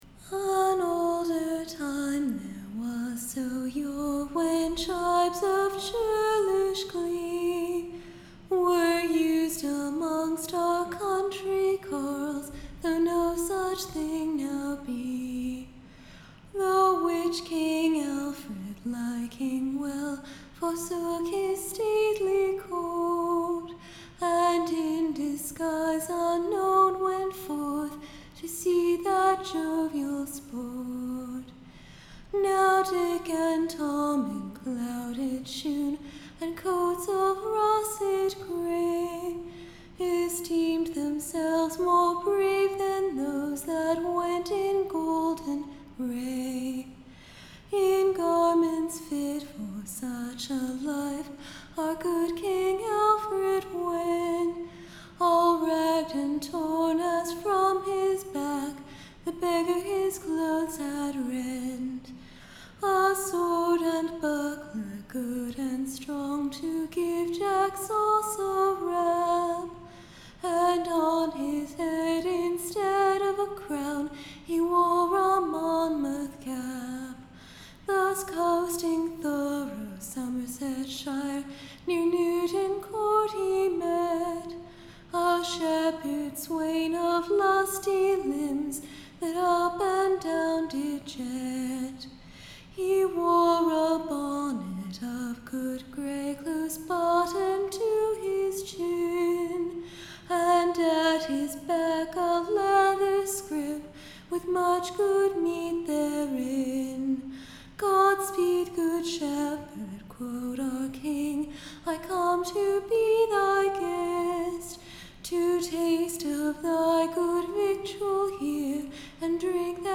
Recording Information Ballad Title Shepheard and the King, and of Gillian the Shepheards VVife, with her / Churlish answers: being full of mirth and merry pastime. Tune Imprint To the tune of Flying Fame.